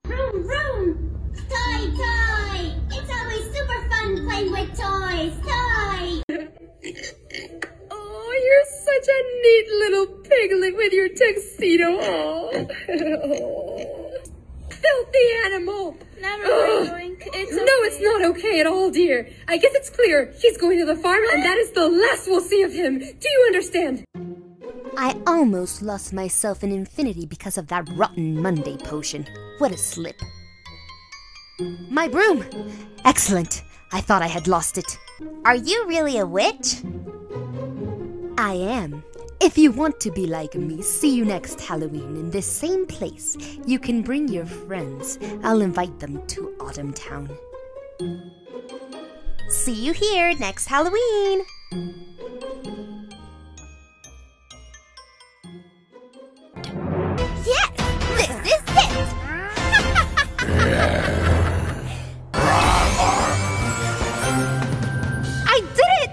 Animation
Wenn Sie eine jugendliche Stimme sowohl für Englisch als auch für Spanisch suchen, werden Sie es nicht bereuen, meine für Ihr Projekt ausgewählt zu haben!
Home Studio, Rode NT1 Kondensatormikrofon der 5. Generation